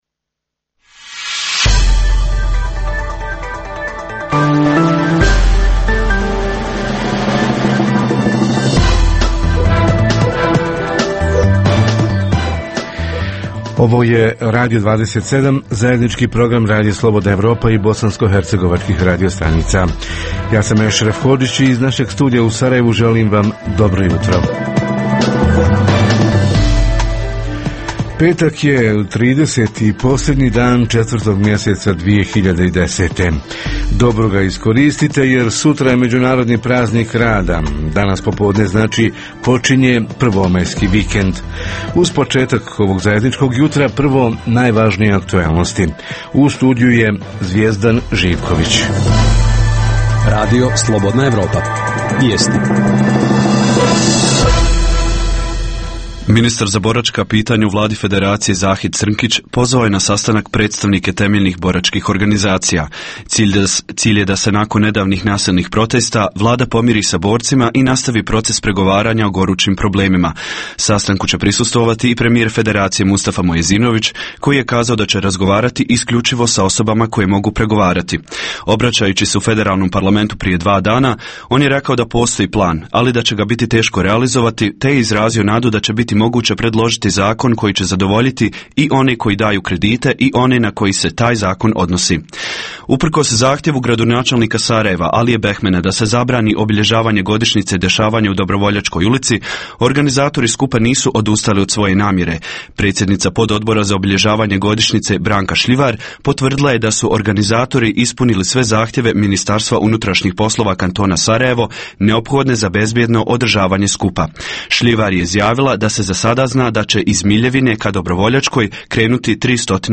Samozapošljavanje u poljoprivredi – utopija, odnosno neostvariv san ili realna mogućnost rentabilnog poslovanja? Reporteri iz cijele BiH javljaju o najaktuelnijim događajima u njihovim sredinama.
Redovni sadržaji jutarnjeg programa za BiH su i vijesti i muzika.